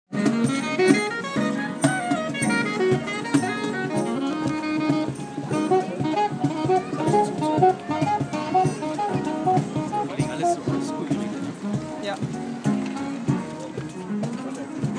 Konzert